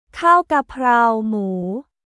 カーオ・ガパオ・ムー